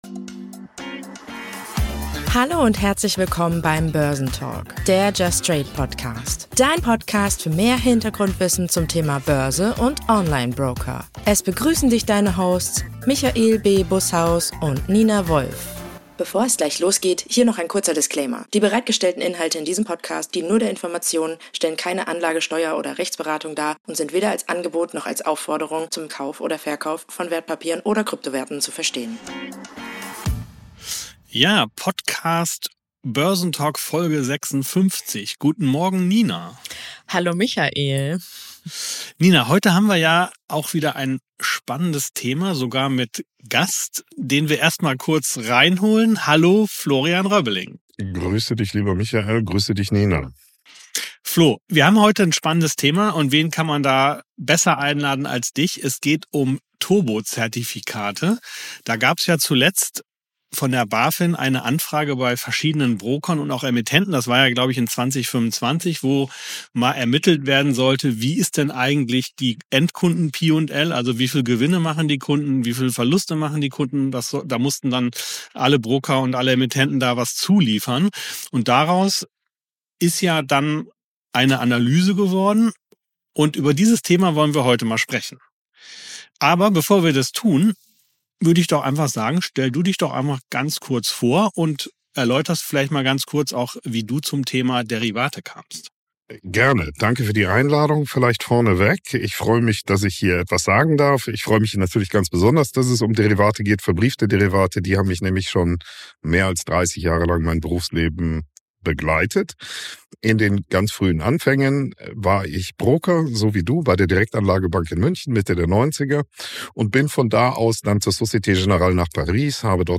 Gemeinsam diskutieren die drei: